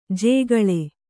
♪ jēgaḷe